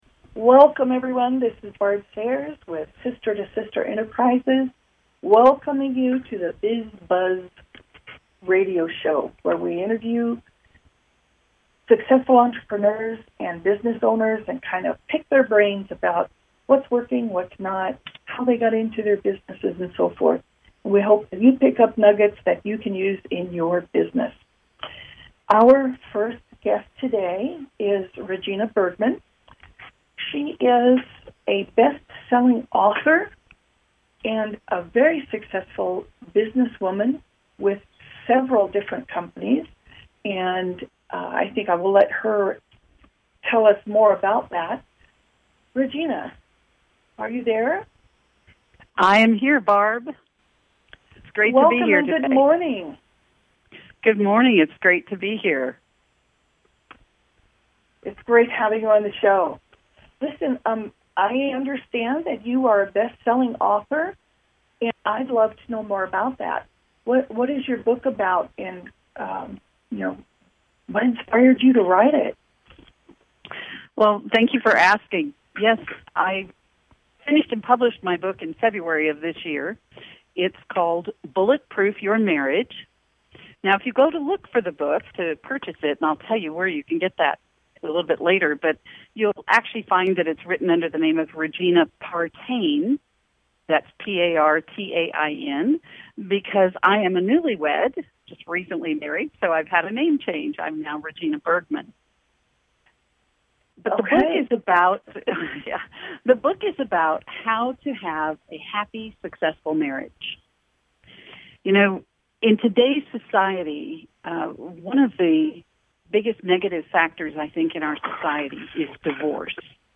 Talk Show Episode
Tune in to share in life’s struggles, folly, laughter, tears… idiosyncratic oddities, cradled by eclectic music selections.